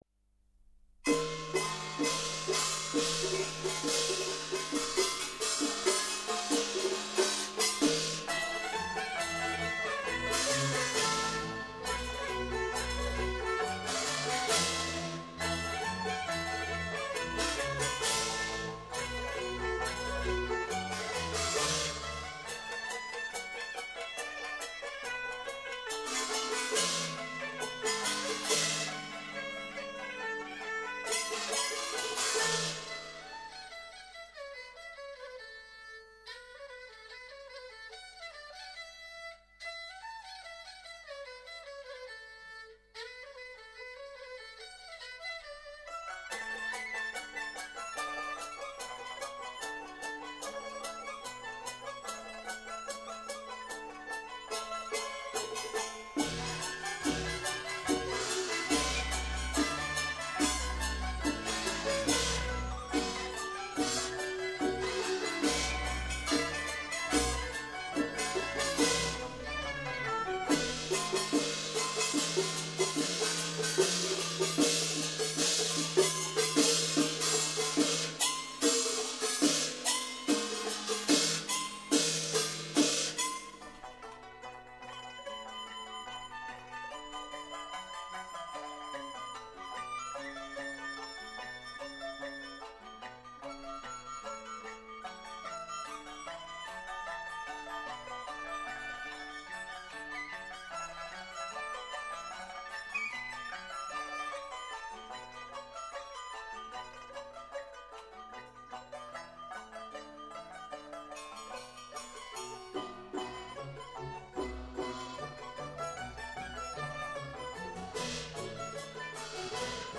浙东锣鼓